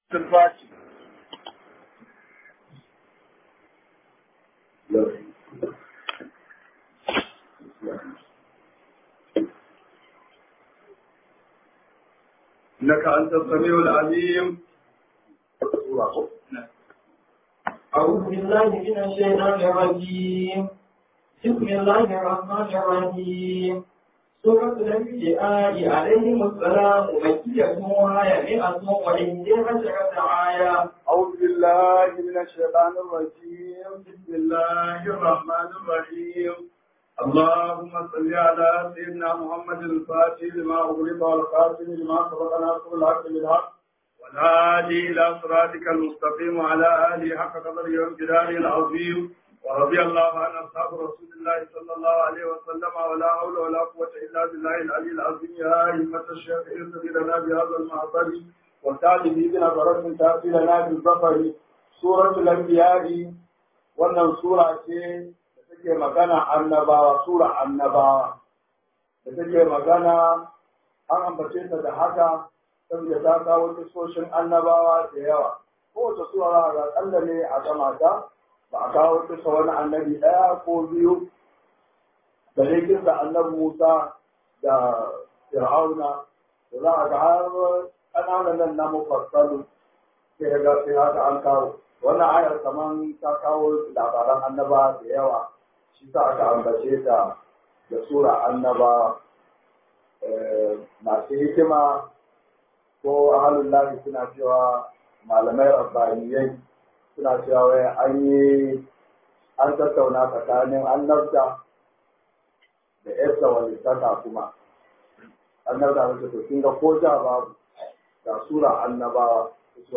_237 TAFSIR (2019_1440).mp3